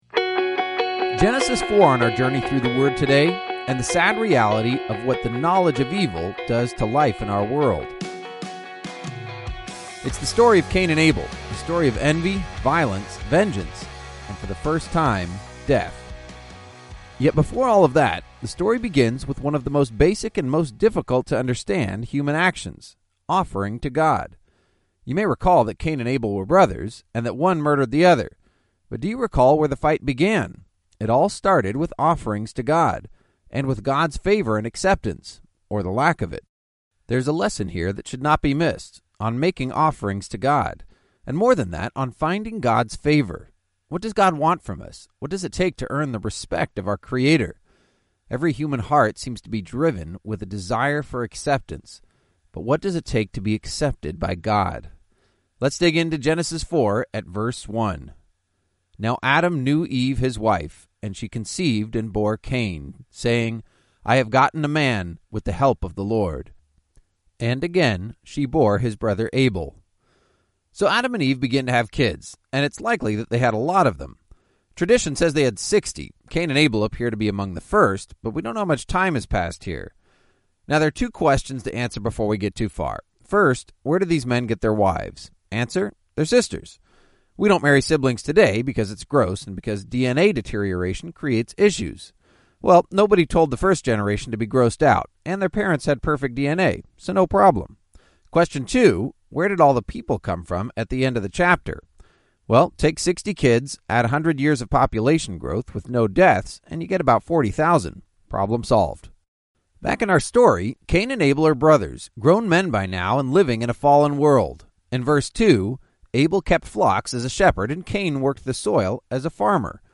19 Journeys is a daily audio guide to the entire Bible, one chapter at a time.